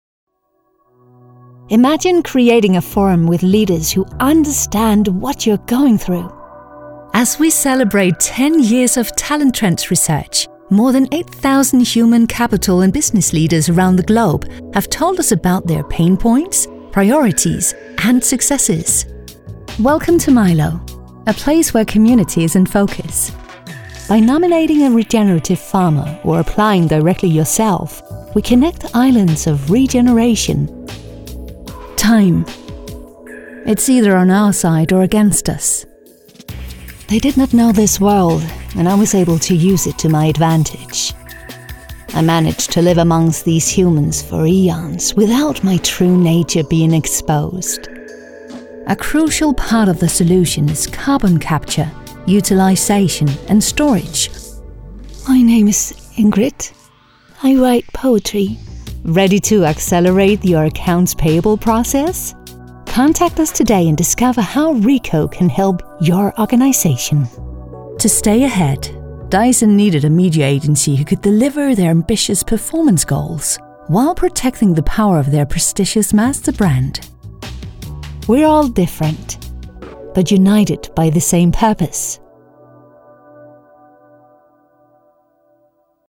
Guias de áudio
Os melhores talentos profissionais de locução e atriz em dinamarquês e inglês, proporcionando a mais alta qualidade de som gravada em um estúdio profissional.